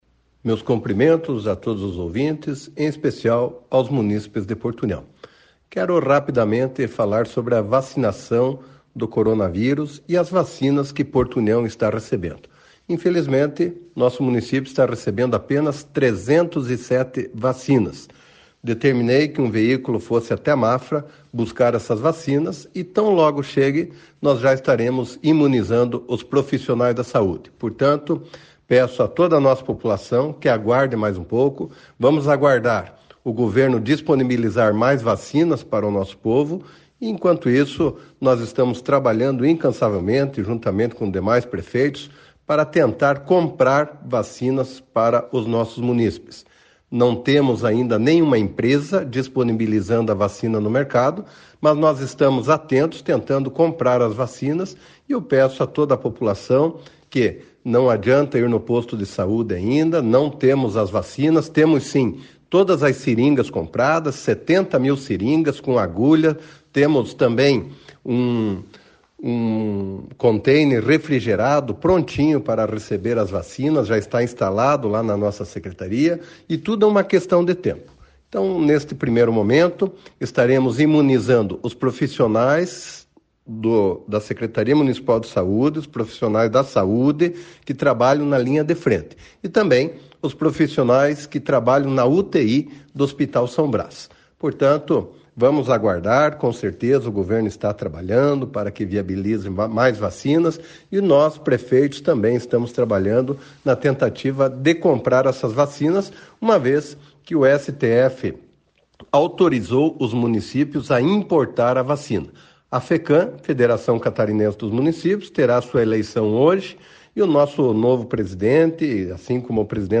Em áudio encaminhado pela sua assessoria de imprensa, o prefeito de Porto União, Eliseu Mibach, afirma que vem fazendo esforços para a vinda de mais vacinas para a cidade.
Prefeito-Eliseu-fala-das-vacina_PEQ.mp3